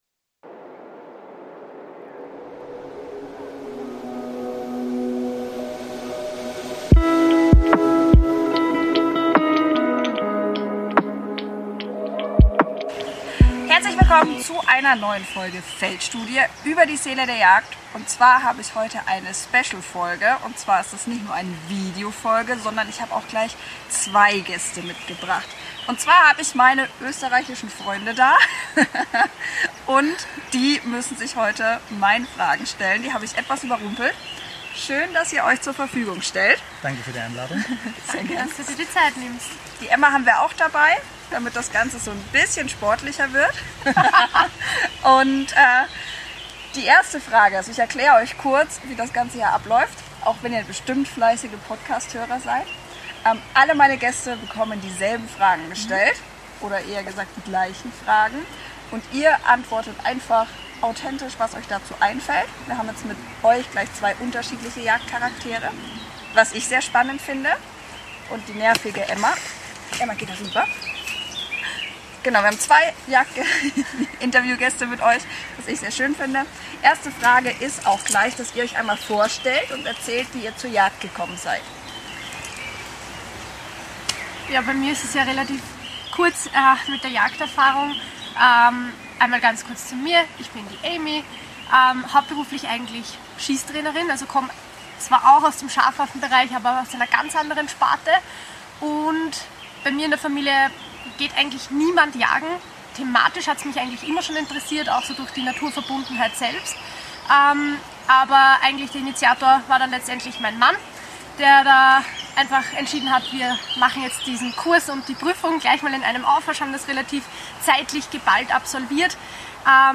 Und was könnte passender sein, als das Mikrofon dorthin zu tragen, wo alles beginnt: ins Revier. Zwischen dem Rascheln des Windes im Wald treffe ich heute zwei außergewöhnliche Gäste aus Österreich.